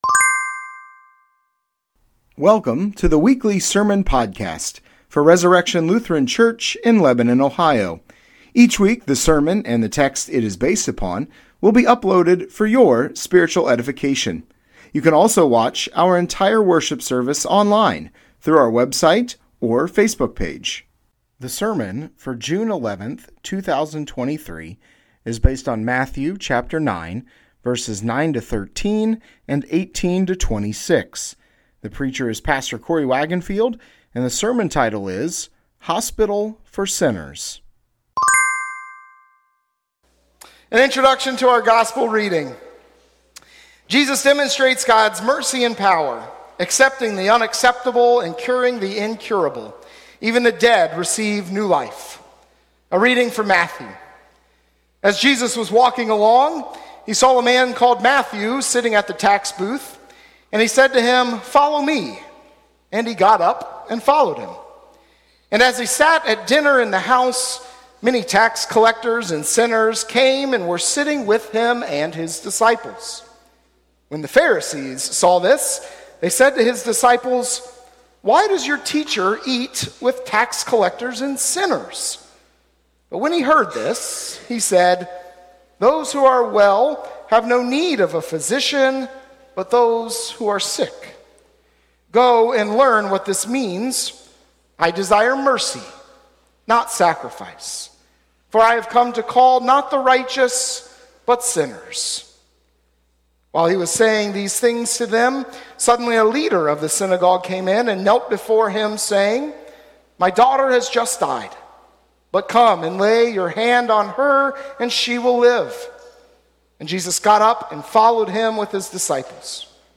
Sermon Podcast Resurrection Lutheran Church - Lebanon, Ohio June 11, 2023 - "Hospital For Sinners"